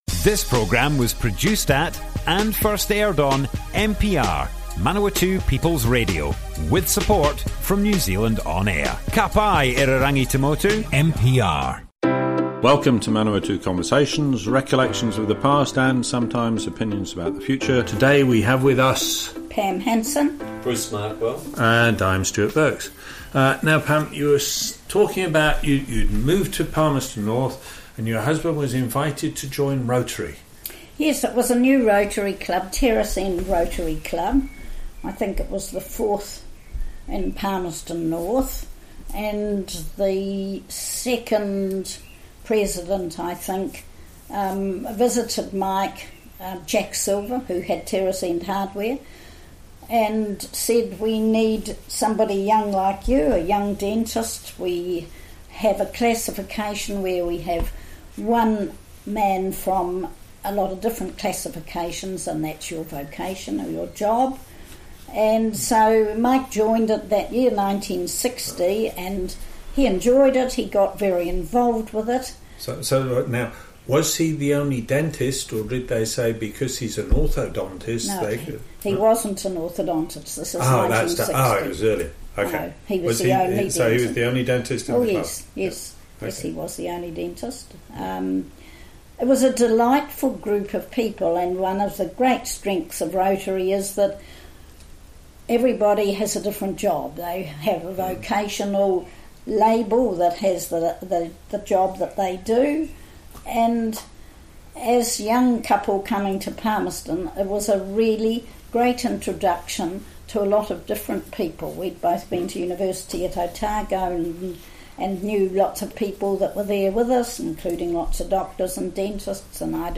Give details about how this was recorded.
Manawatu Conversations More Info → Description Broadcast on Manawatu People's Radio, 21st January 2020.